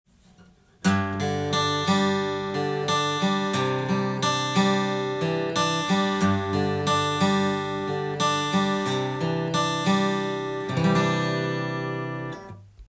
１２～５３に比べて低音の雰囲気は落ちますが、音の艶は維持しています。